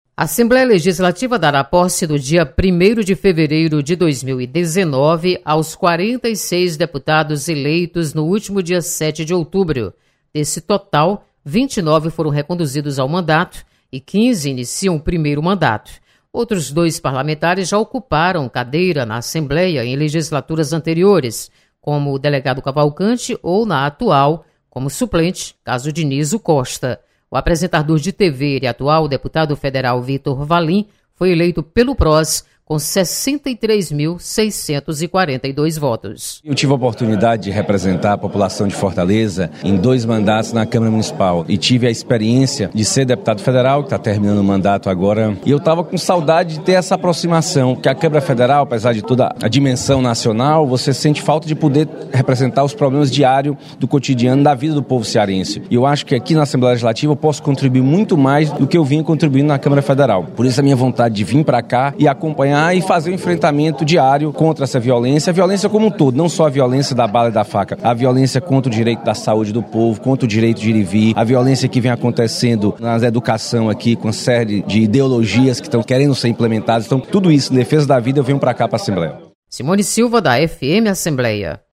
Posse dos deputados eleitos acontece no dia primeiro de fevereiro de 2019. Repórter